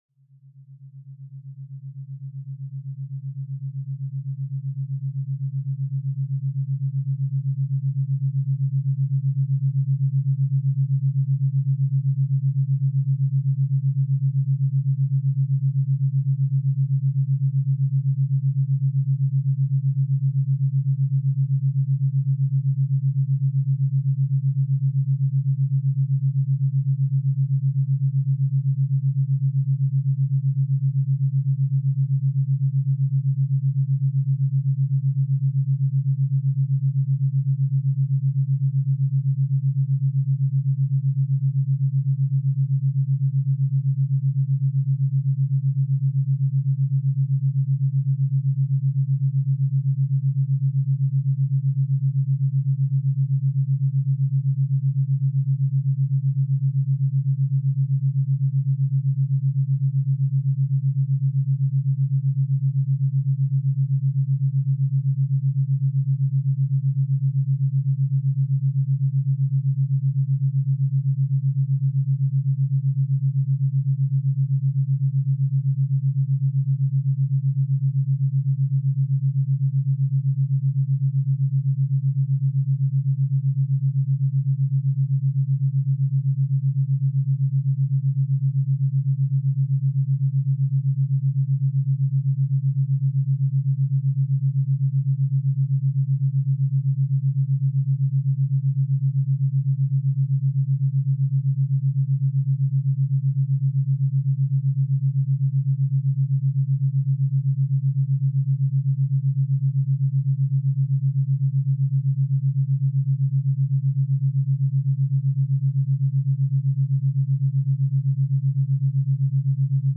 勉強BGM